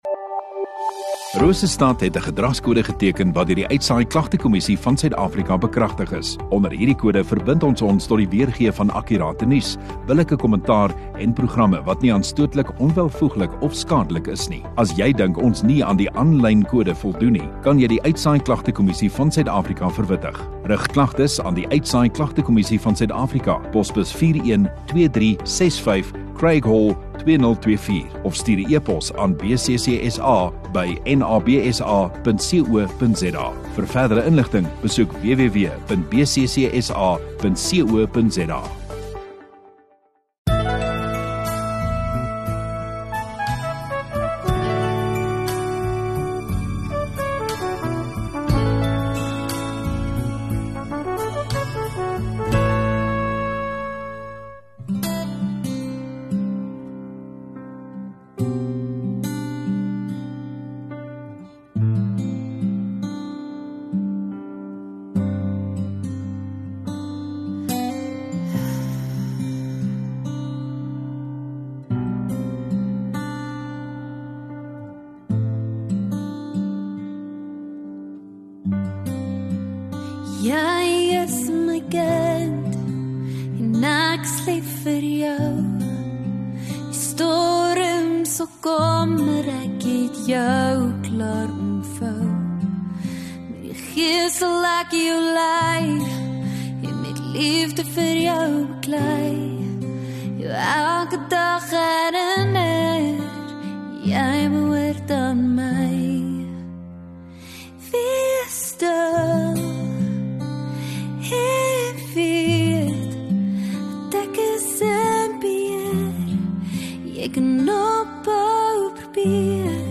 15 Jun Sondagaand Erediens